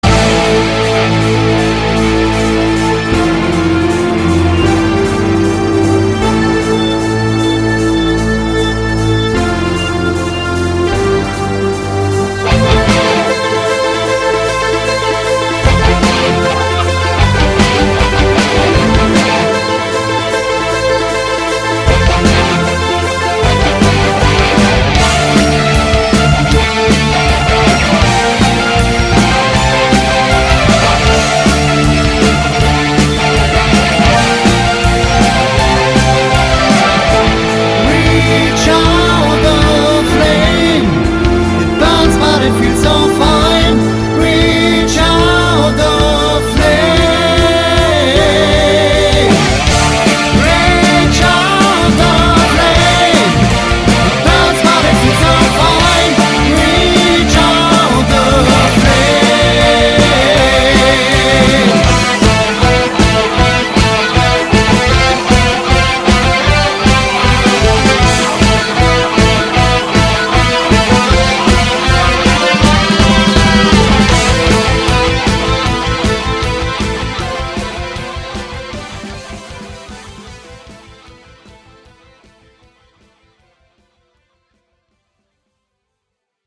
Keyboards
Bass
E-Gitarre
Gesang
Schlagzeug